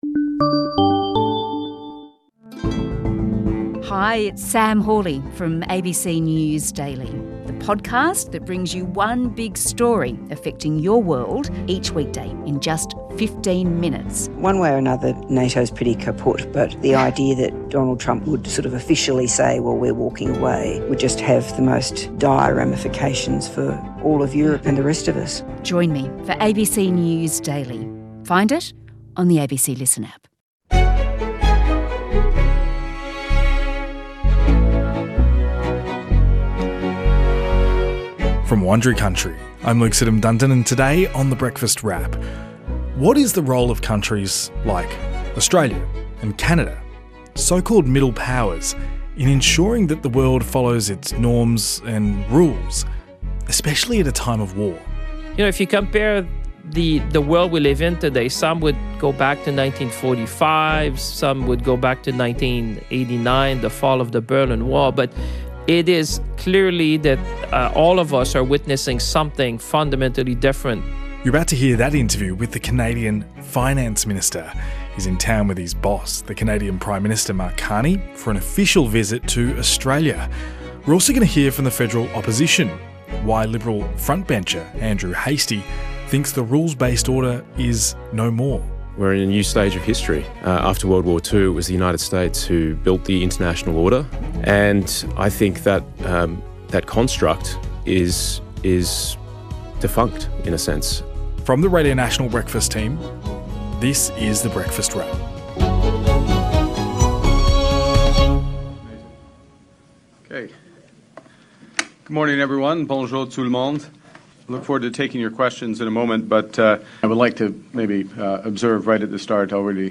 We hear from a senior Canadian Minister about the role of middle powers like Canada and Australia, as the Canadian PM lands in the country for an official visit.